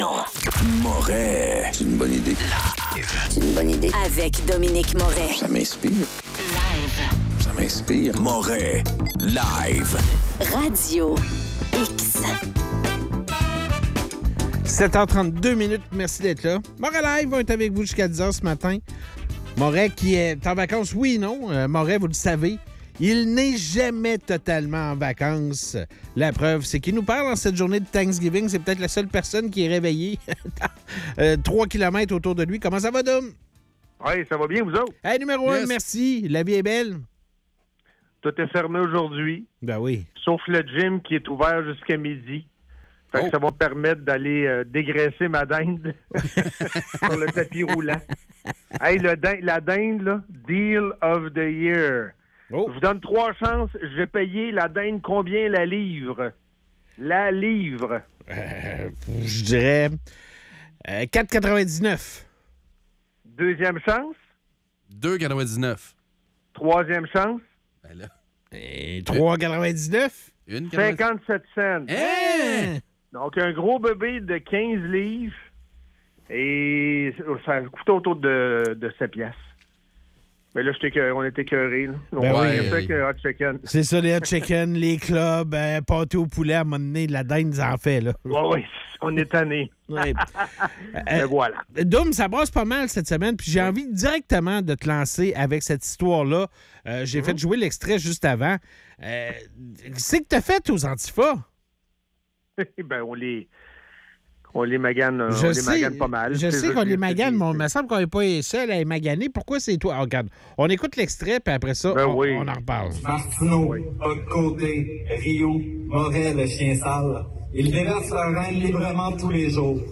en direct de Floride